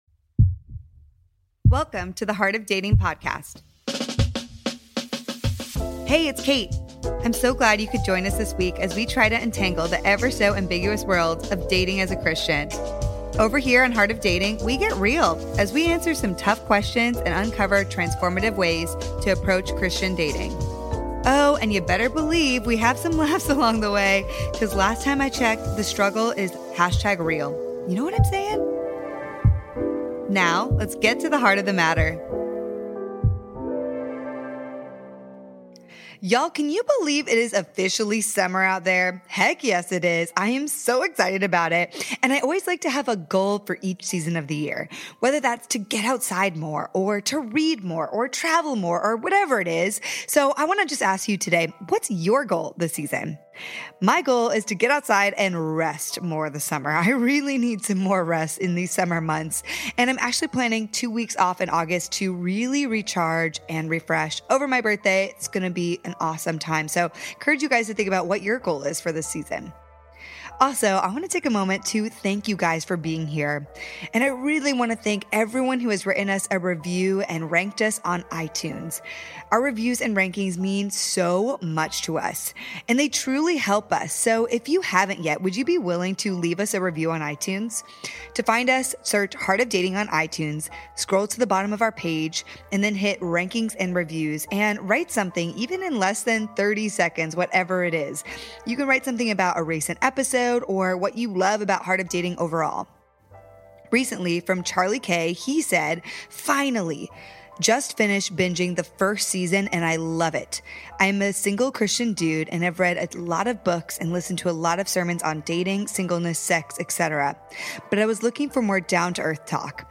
Single Men Panel: How to avoid friendationships, how to plan a first date out, and seeking mentorship from other men.
This conversation is split into two parts- first questions FROM the dudes and then next questions from the LADIES.